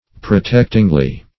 Search Result for " protectingly" : The Collaborative International Dictionary of English v.0.48: Protectingly \Pro*tect"ing*ly\, adv.